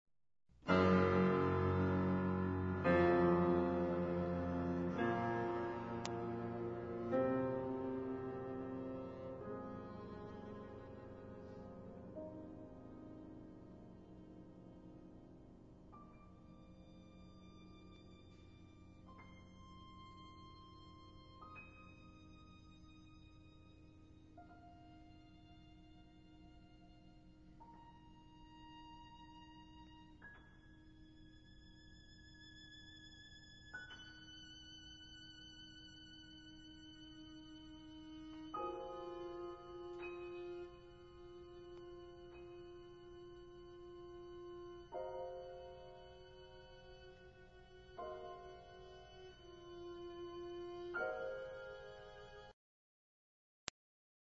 wistful; III. vehement